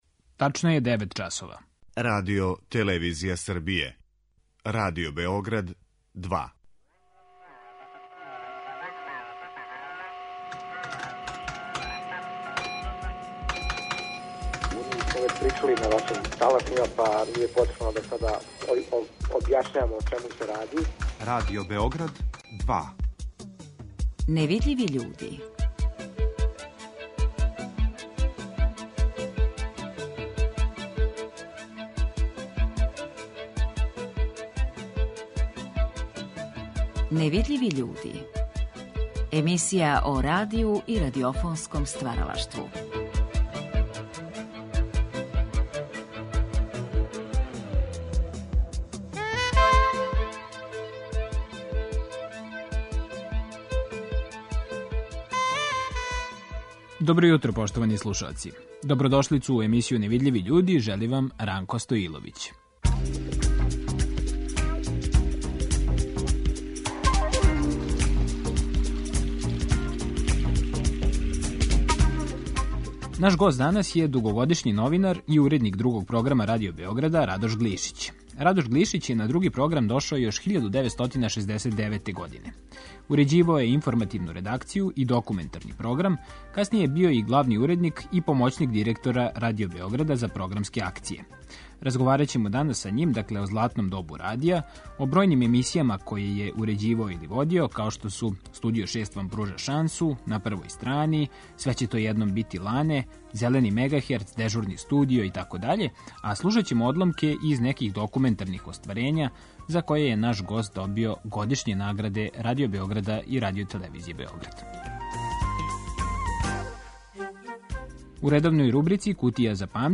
Реч је о разговору који је вођен за циклус емисија „Гост Другог програма" 1975. године.